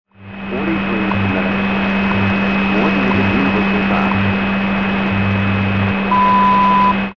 WWV Fort Collins, Colorado 5000 World Time (Atomic Clock)
The radio used to receive these stations is the Grundig S350.